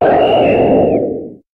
Cri de Type:0 dans Pokémon HOME.